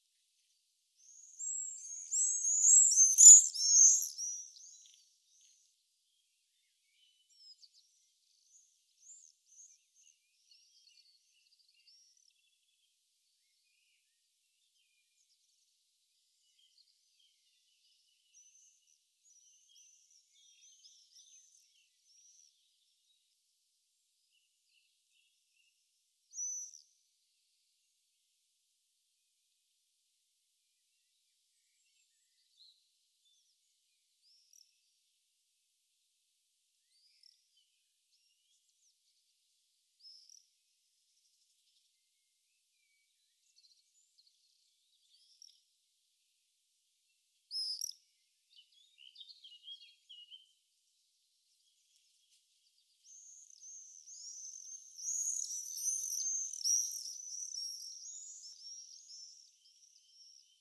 Apus apus
Canto
O seu berro agudo e característico, que emiten mentres voan en grupos sobre as cidades, é un sinal inconfundible da chegada do verán.